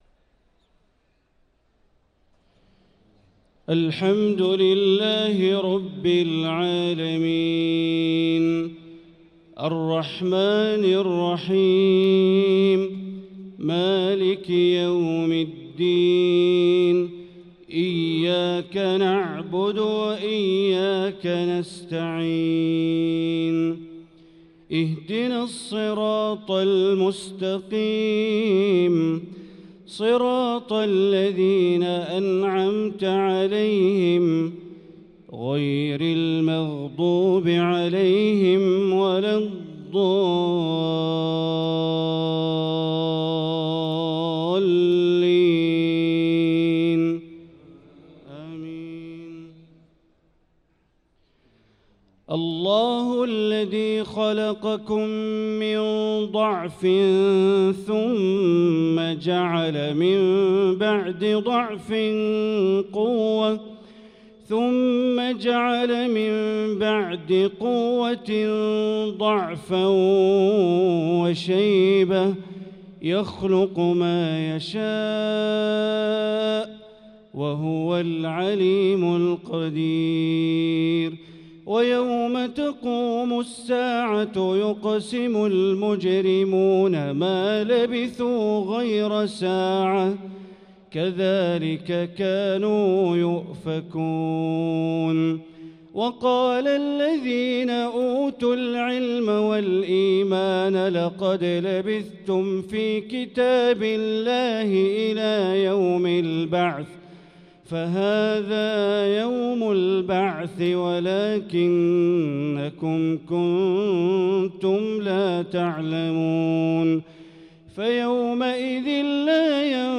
صلاة العشاء للقارئ بندر بليلة 3 رمضان 1445 هـ